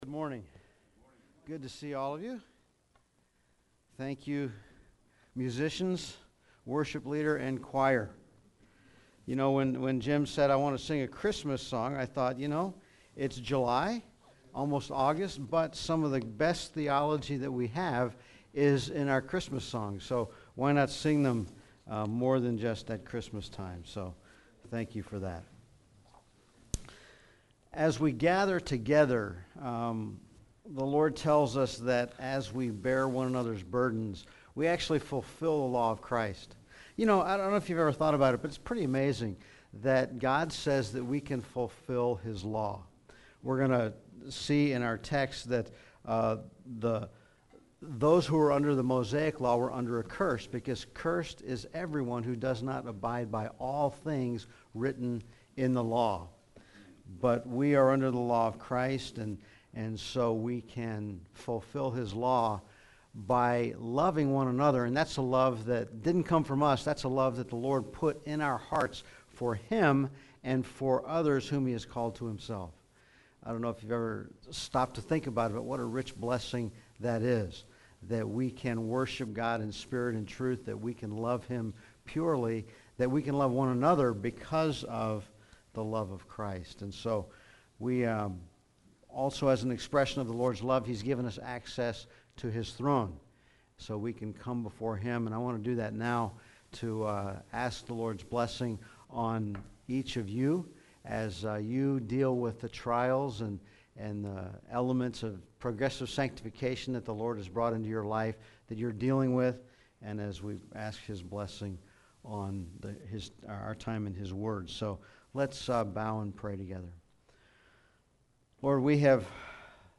A concluding sermon on the prophet Malachi’s final admonition to Israel.